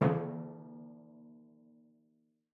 Timpani6C_hit_v5_rr1_main.mp3